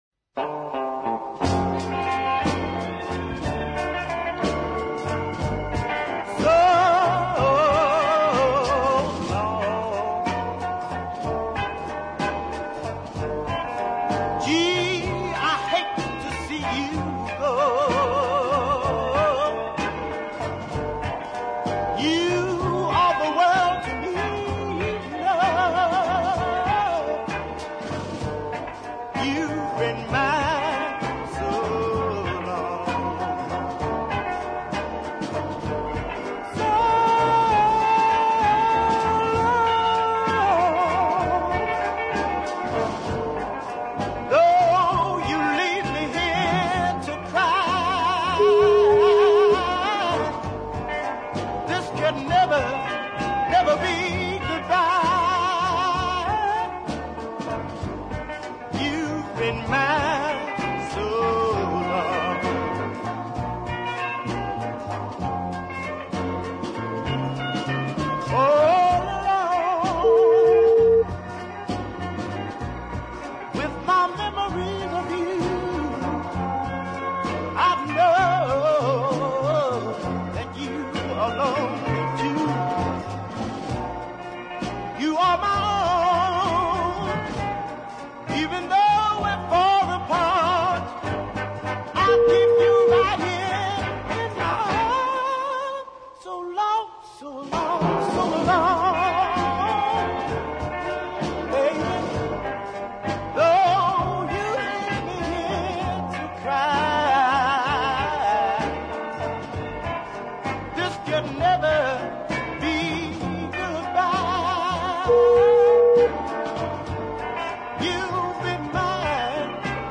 The deep ballad
especially when he soars into his falsetto range